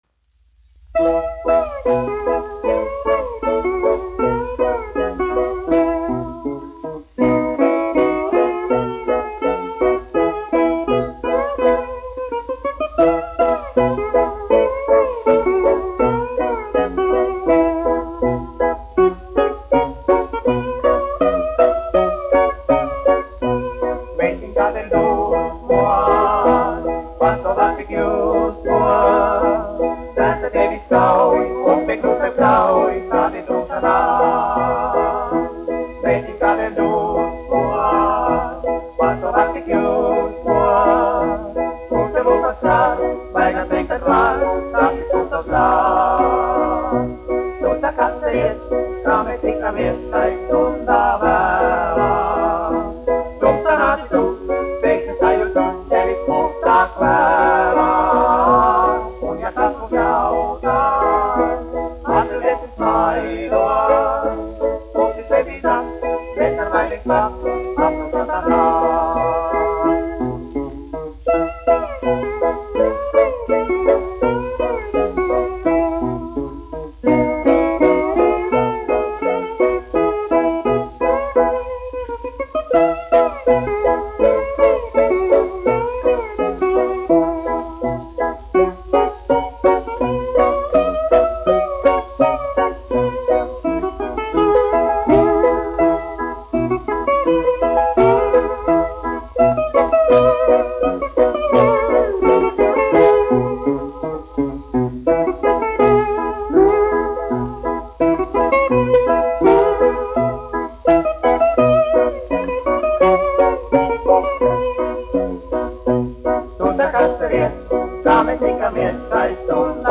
1 skpl. : analogs, 78 apgr/min, mono ; 25 cm
Fokstroti
Populārā mūzika
Latvijas vēsturiskie šellaka skaņuplašu ieraksti (Kolekcija)